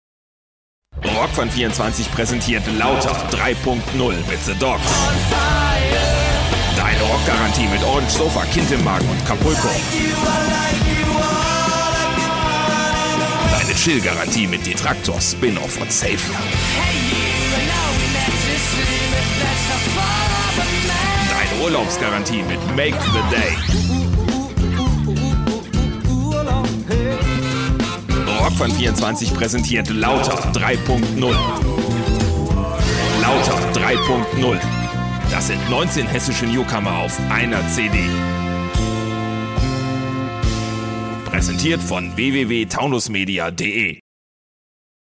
Werbejingle entnehmen.